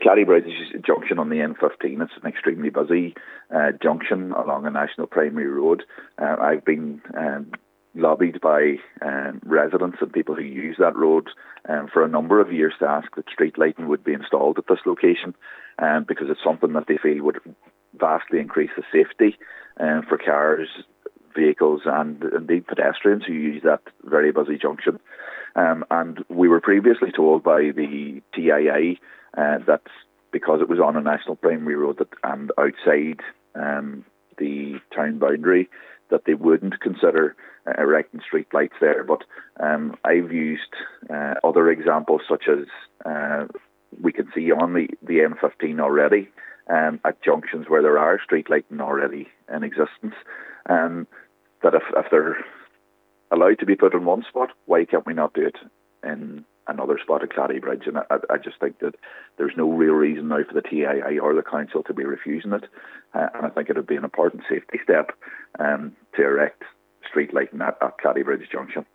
Councillor Gary Doherty says its important action is taken to address fears there: